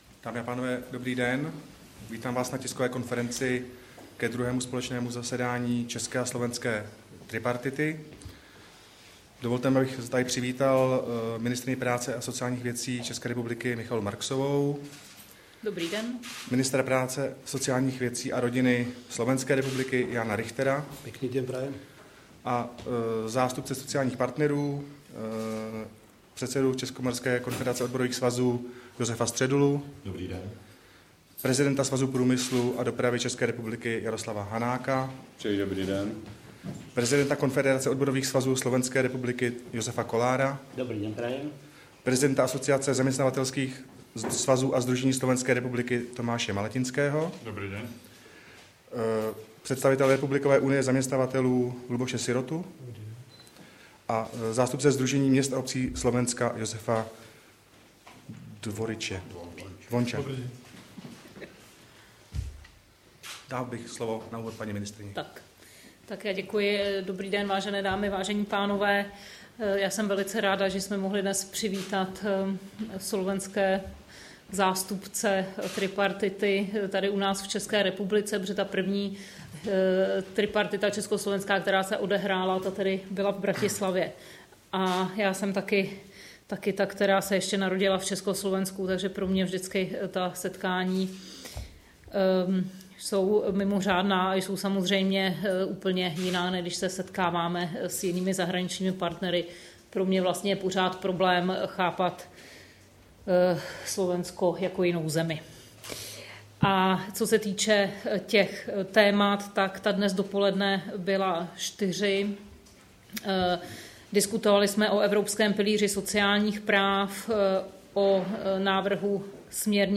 Tisková konference po společném zasedání Rad hospodářské a sociální dohody České a Slovenské republiky, 30. března 2017